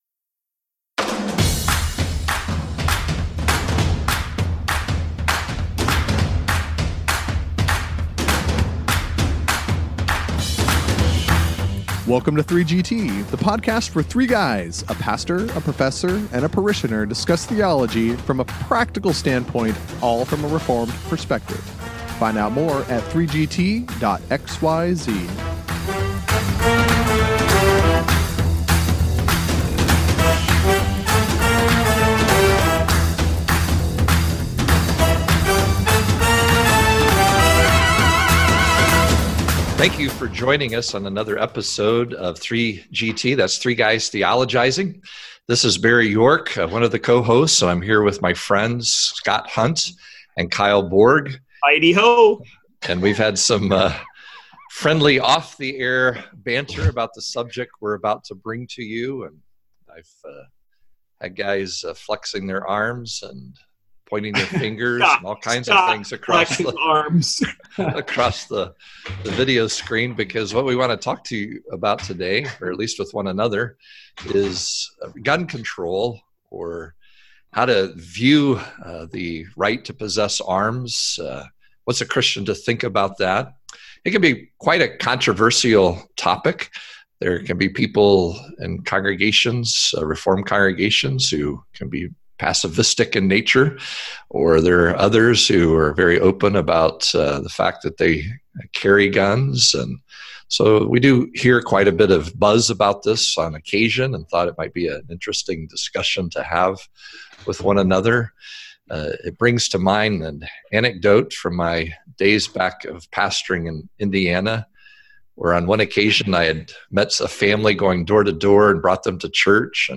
They discuss the Second Amendment, the Biblical warrant for defending oneself, the role of government in shaping laws regarding guns, and how Christians should discuss these things. In the back and forth banter